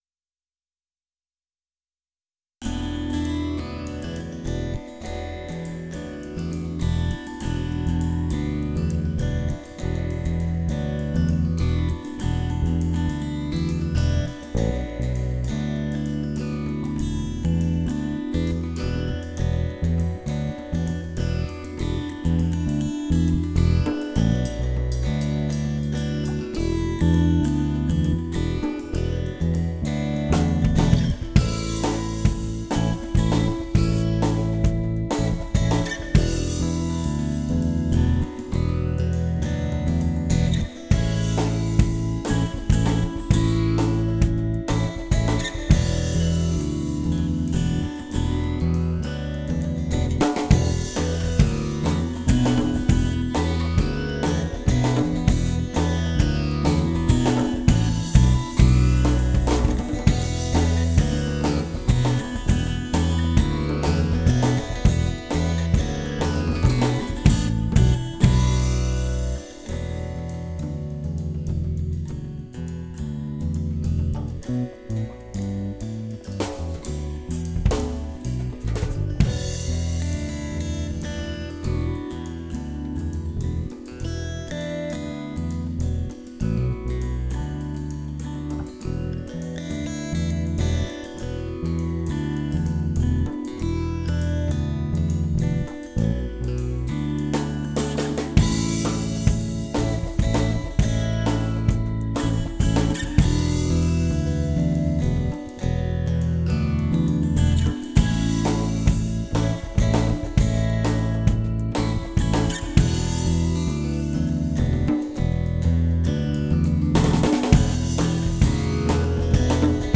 welcome-inside-no-vocals-01.wav